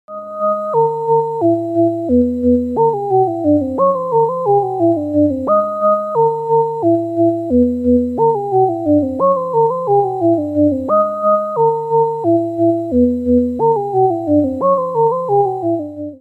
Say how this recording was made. Sound quality is excellent using a 12-bit DAC, 32kHz sample rate and 32-bit precision DSP computations. Sequence produced in 'CV Mode'